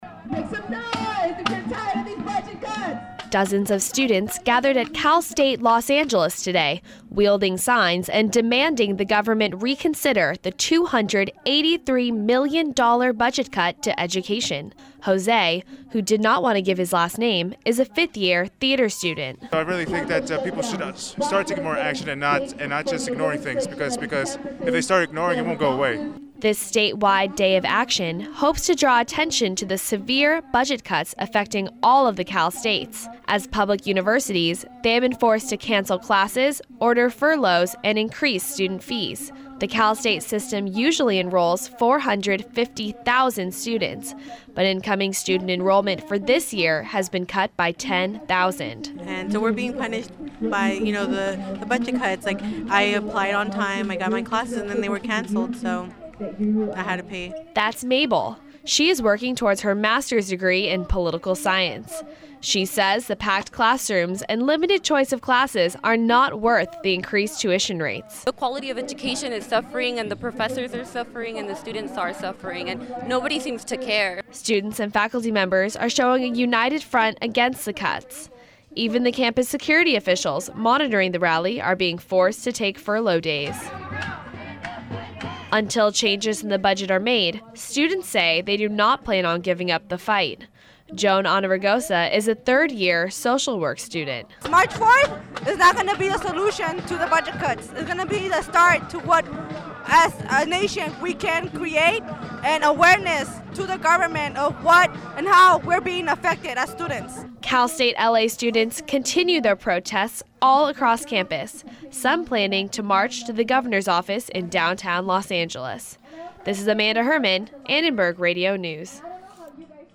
“Make some noise if you’re tired of these budget cuts!” protesters yelled on the Cal State Los Angeles earlier today.